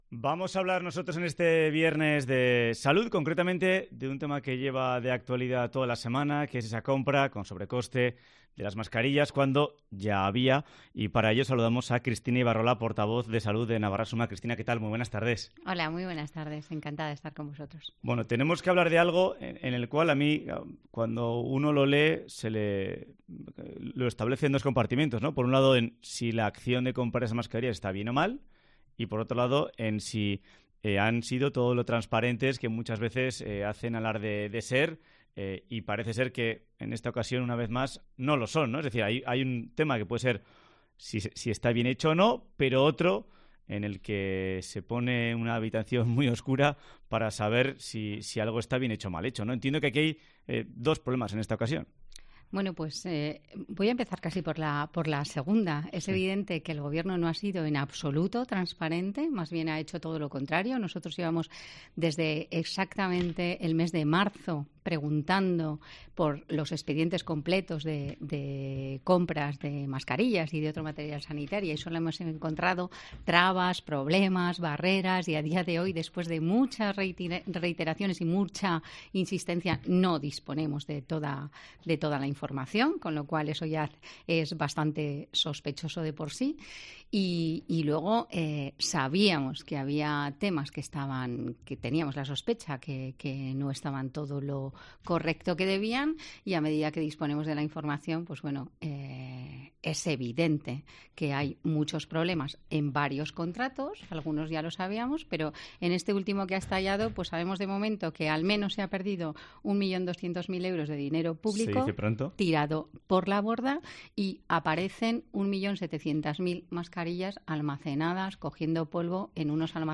Entrevista en COPE a Cristina Ibarrola de UPN